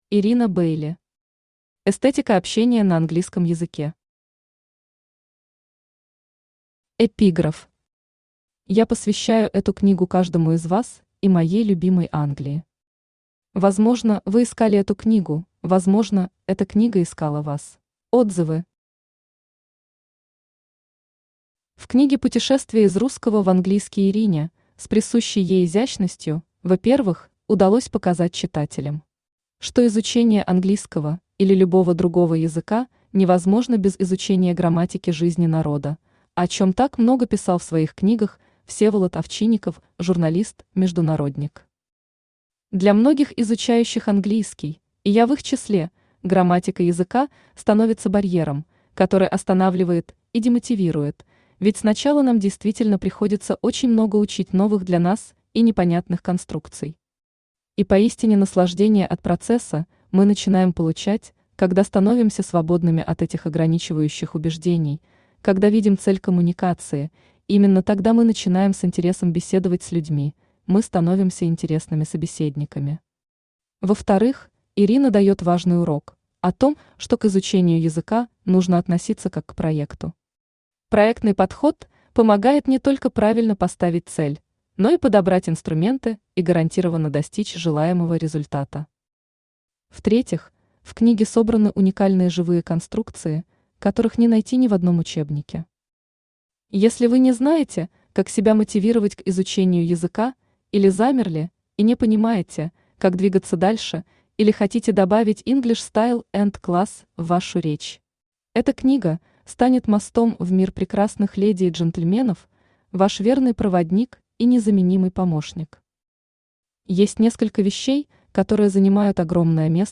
Aудиокнига Эстетика общения на английском языке Автор Ирина Бэйли Читает аудиокнигу Авточтец ЛитРес.